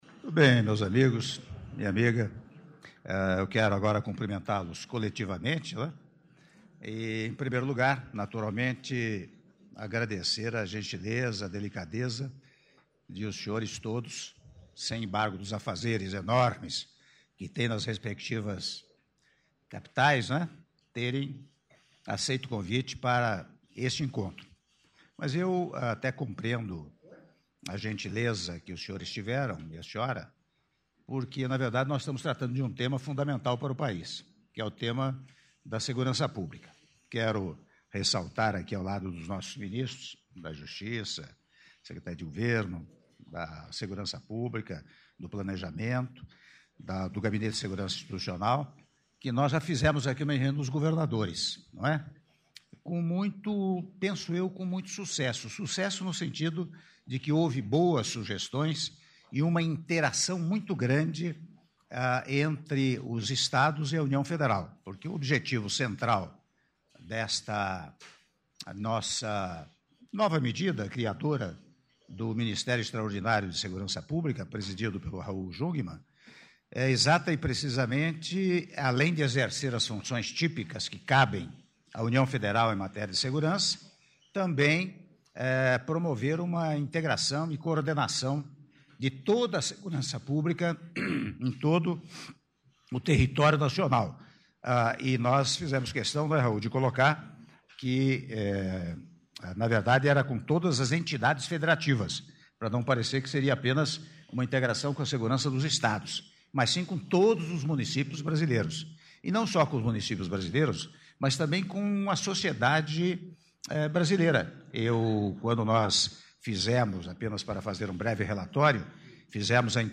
Áudio do discurso do Presidente da República, Michel Temer, na abertura da Reunião com Prefeitos das Capitais Brasileiras - (07min47s) - Brasília/DF